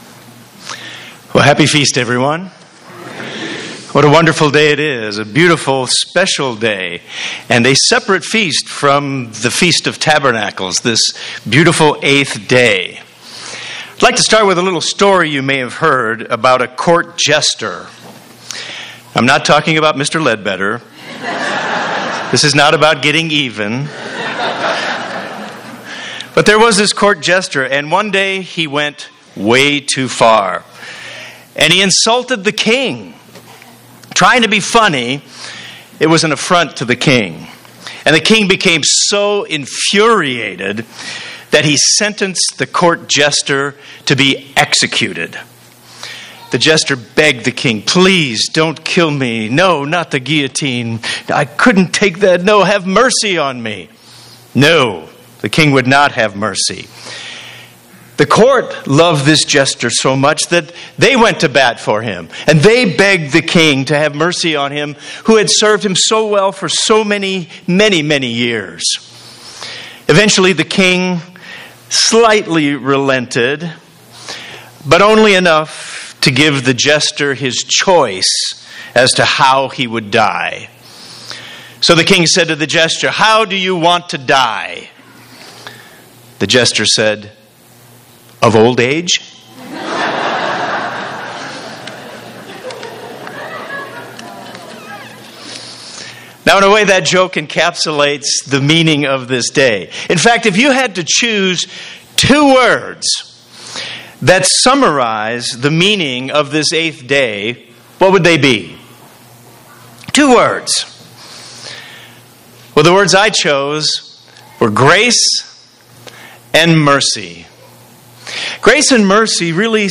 This sermon was given at the Lake Junaluska, North Carolina 2019 Feast site.